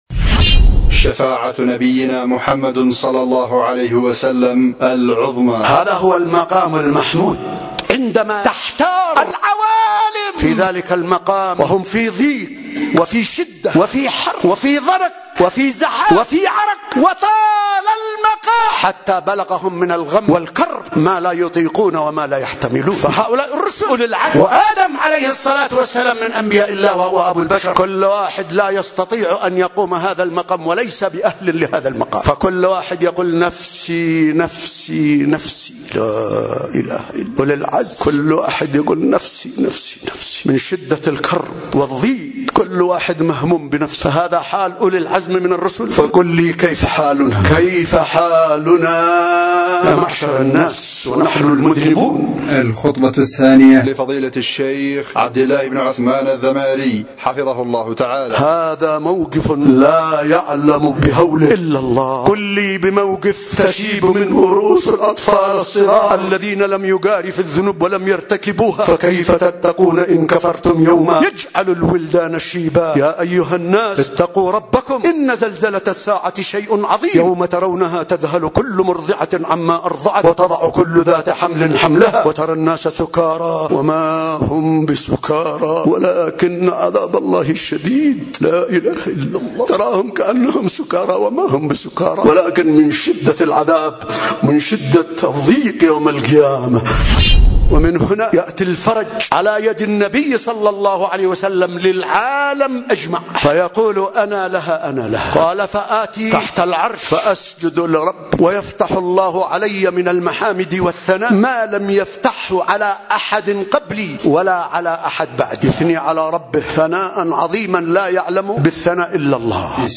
الخطبة الثانية
ألقيت في مسجد الصديق بمدينة ذمار